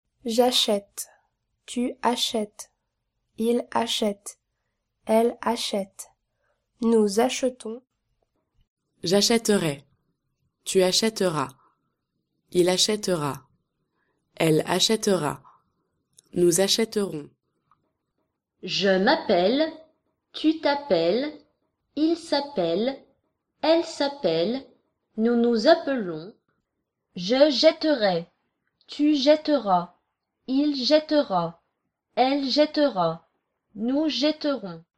Scan de QR code op de taalkaart met je smartphone of tablet en beluister de rijtjes. Ingesproken door native speakers, uiteraard!